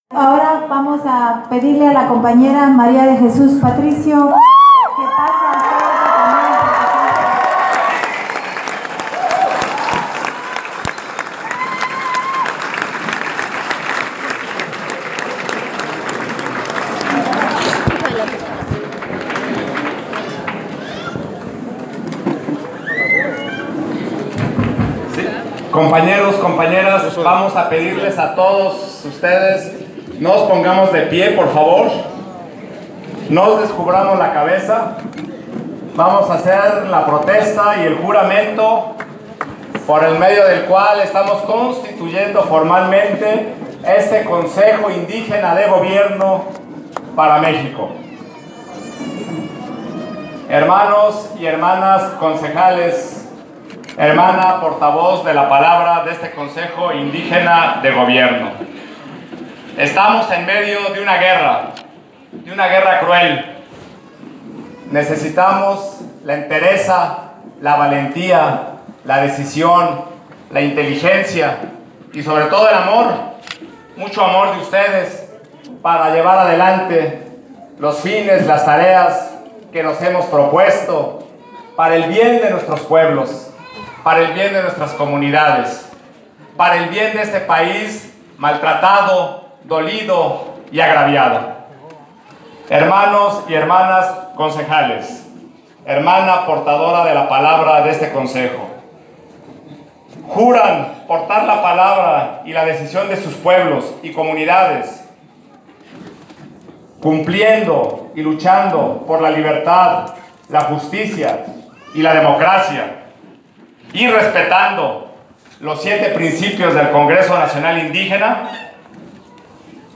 Aquí les compartimos los audios de la sesión pública de la Asamblea Constitutiva del Consejo Indígena de Gobierno para México, realizada el 28 de mayo de 2017 en el CIDECI-Unitierra en San Cristóbal de las Casas, Chiapas: